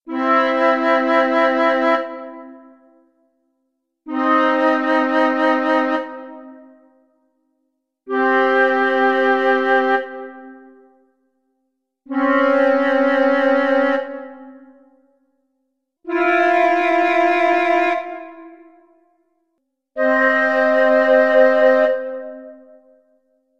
Ed ecco, in audio, i rapporti matematici tra le velocità dei pianeti riportate nello schema, presentati in successione: